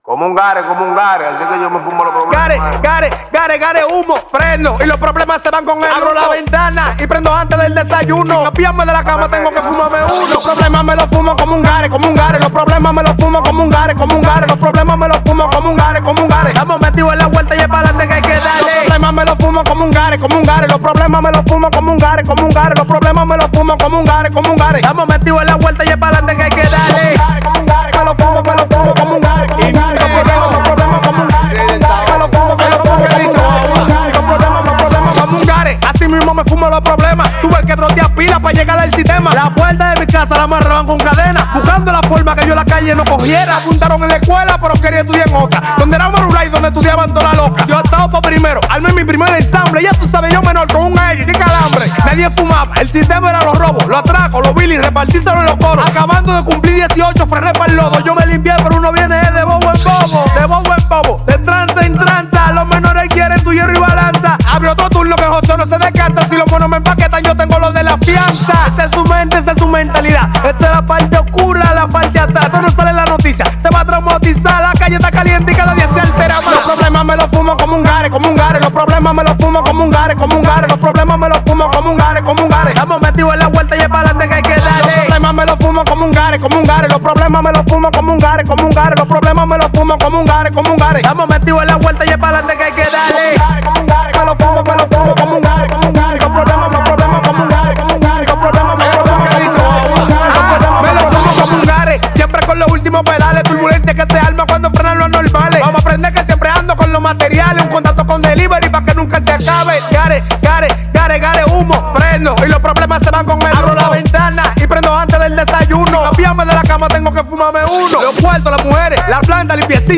Genre: Dembow.